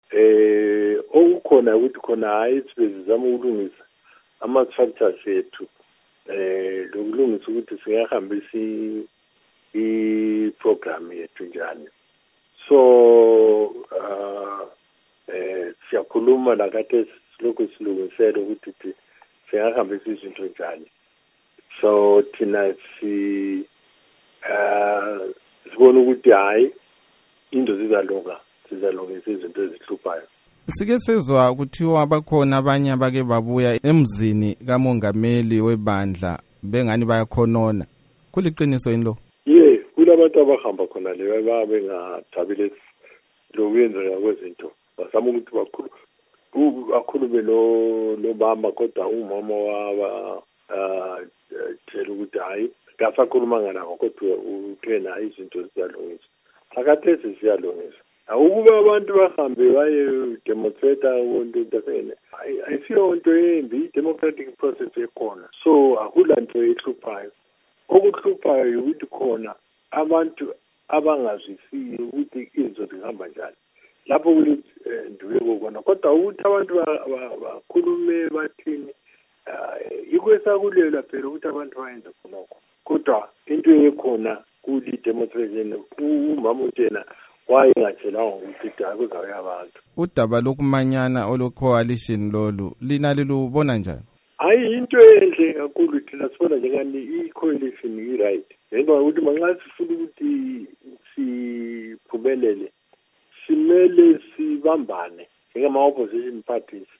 Ingxoxo loMnu. Rugare Gumbo